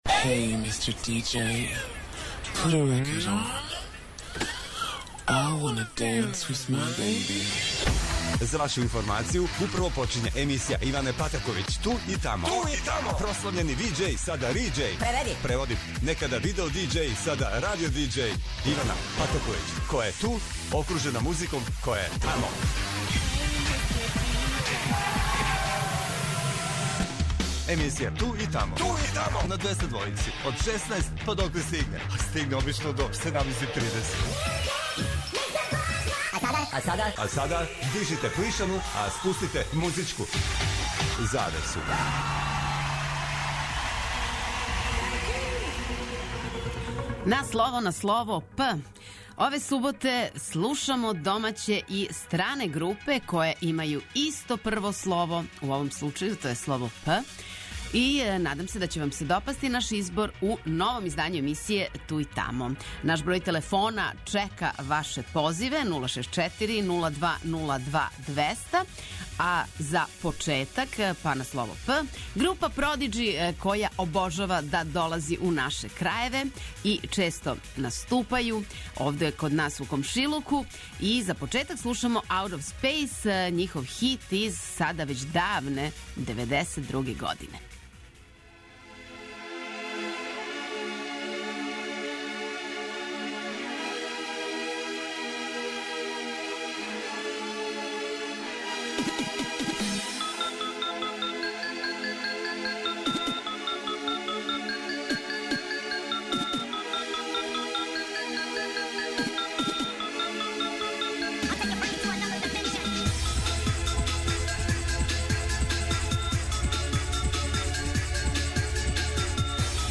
На слово на слово 'П'...У новом издању емисије 'Ту и тамо' слушаћемо популарне групе које повезује исто почетно слово.
Очекују вас велики хитови, страни и домаћи, стари и нови, супер сарадње, песме из филмова, дуети и још много тога.